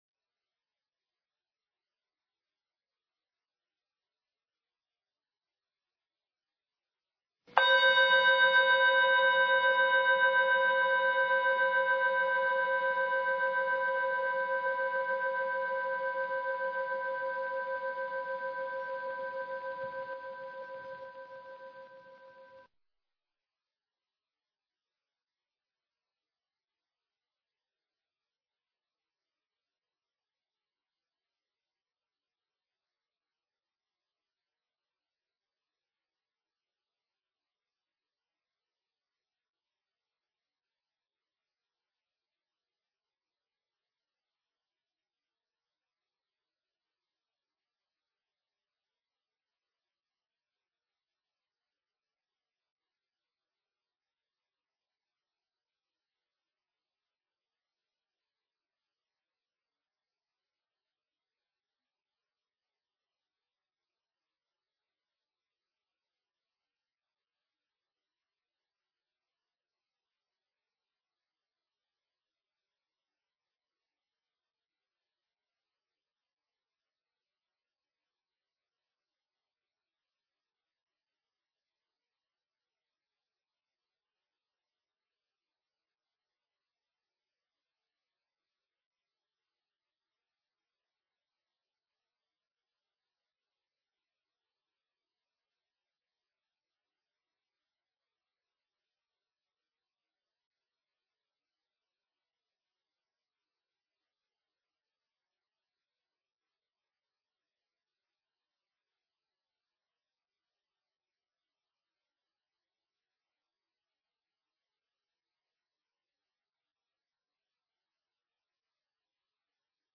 Tiếng chuông đầu tiên thông báo để vào thư giản và điều thân. 2.
Tiếng chuông thứ nhì thông báo vào thư giản và Quán Số Điện Tử. Ba tiếng chuông liên tiếp cuối cùng là Hồi Hướng và Xả Thiền.